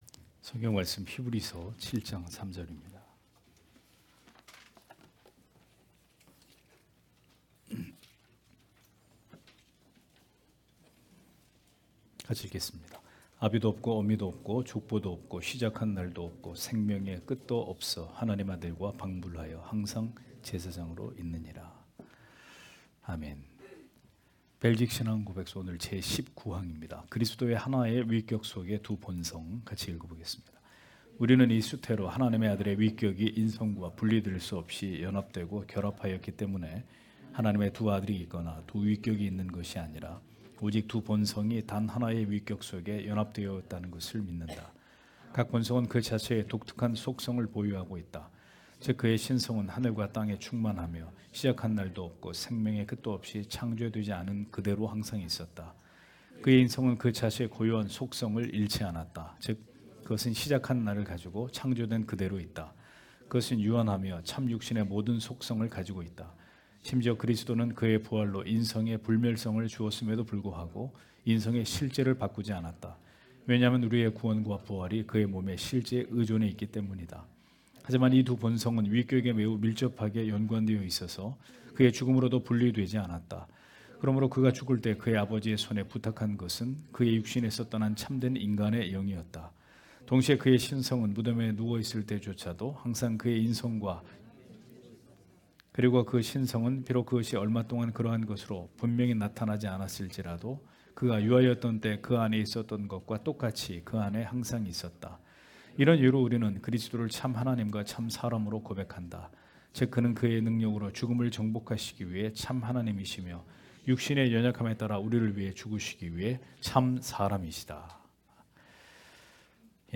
주일오후예배 - [벨직 신앙고백서 해설 20] 제19항 그리스도의 하나의 위격 속의 두 본성 (히 7장 3절)
* 설교 파일을 다운 받으시려면 아래 설교 제목을 클릭해서 다운 받으시면 됩니다.